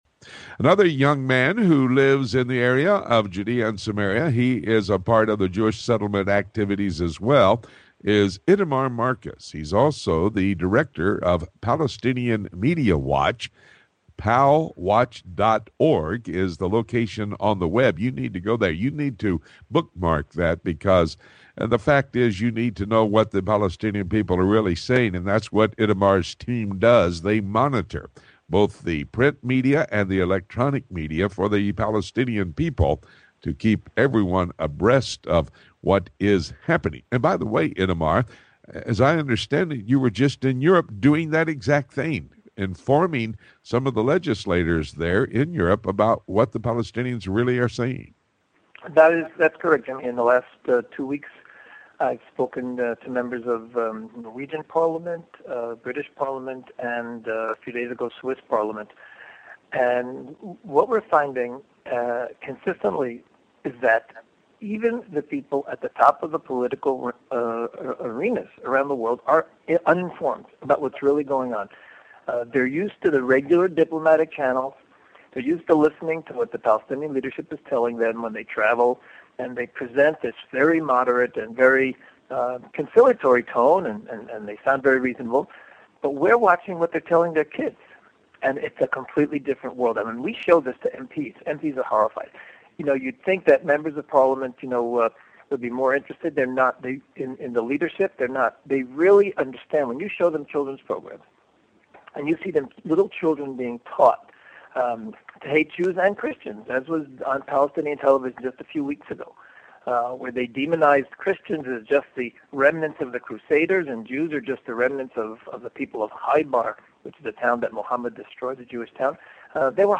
Weekend Interviews – June 9, 2012